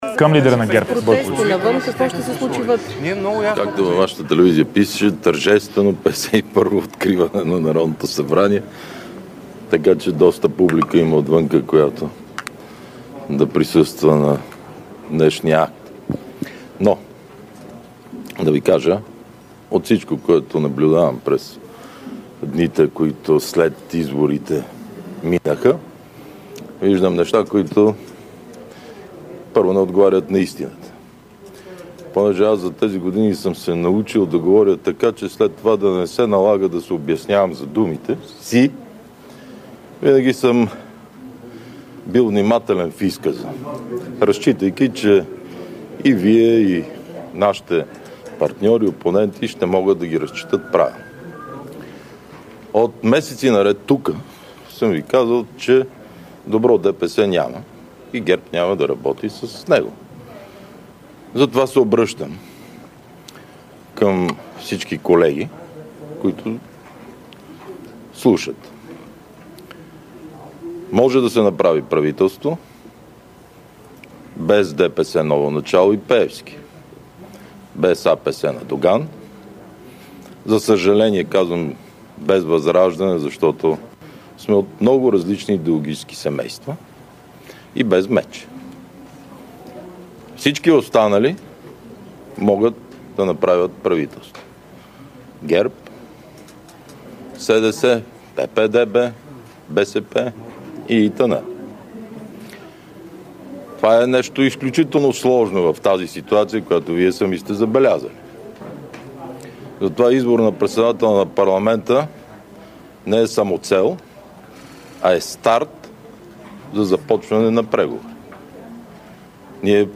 9.30 - Брифинг на председателя на ГЕРБ Бойко Борисов. - директно от мястото на събитието (Народното събрание)